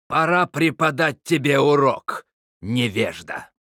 Нам удалось найти в клиенте фразы и локализованную озвучку Кадгара.
Локализованные фразы Кадгара
VO_HERO_08b_THREATEN_05.wav